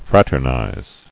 [frat·er·nize]